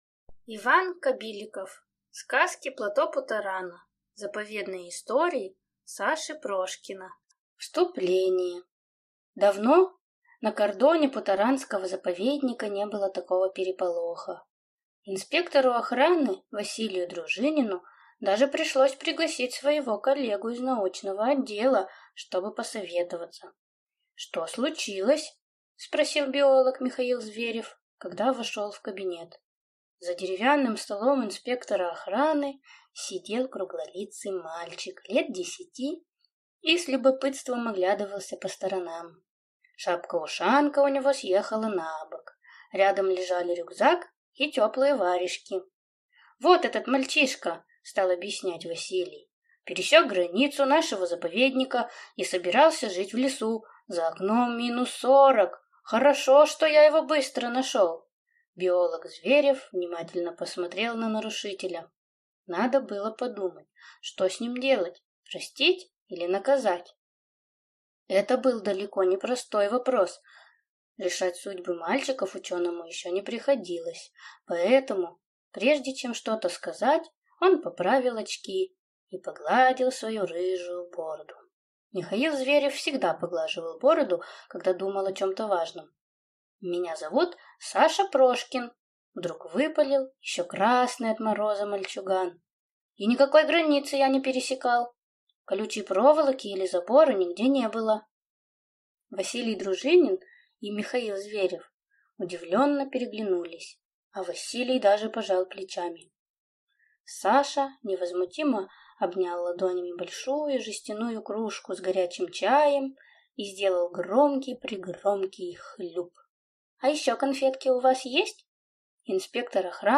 Аудиокнига Сказки плато Путорана | Библиотека аудиокниг